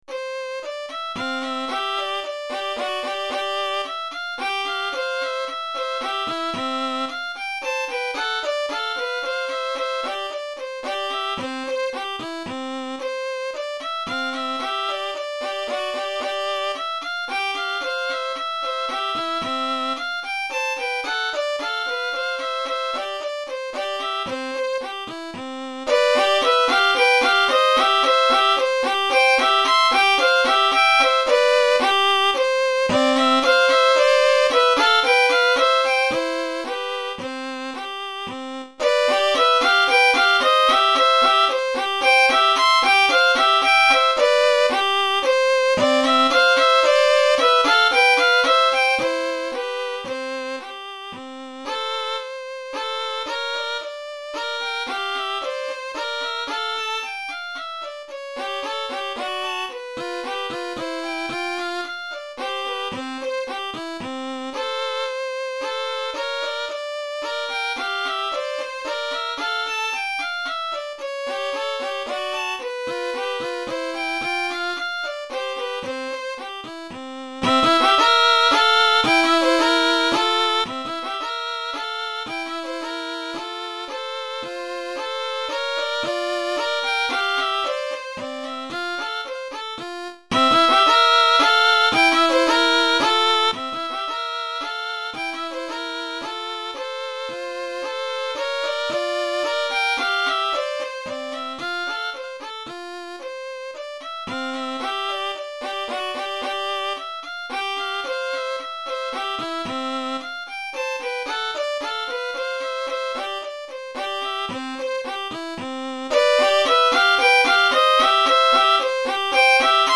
Répertoire pour Violon - 2 Violons